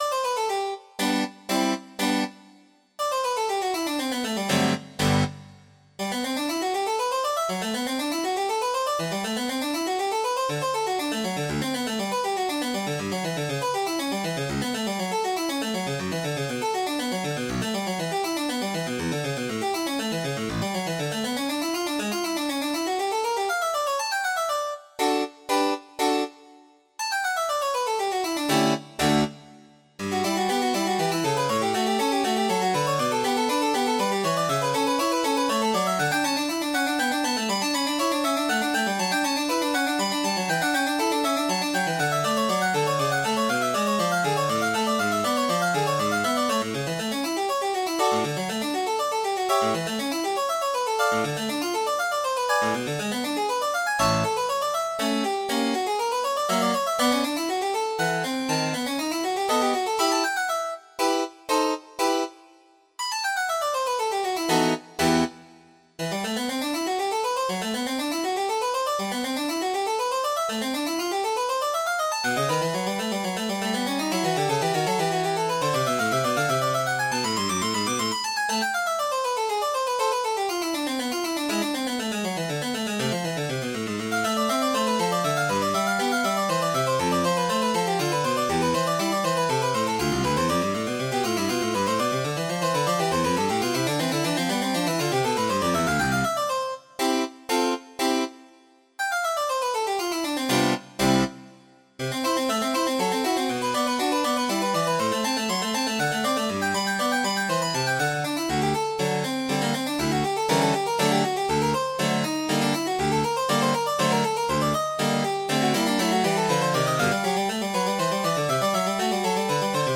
Piano  (View more Intermediate Piano Music)
Classical (View more Classical Piano Music)
18th century    baroque